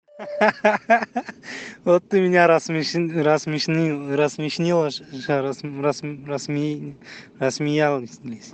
• Качество: 192, Stereo
голосовые
смех
с акцентом